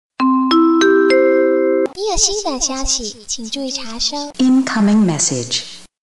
newMessage.wav